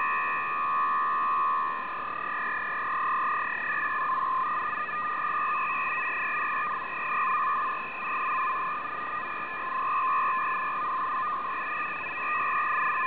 MCVFT-systems (Multichannel VFT)
3 x FEC-150 Bd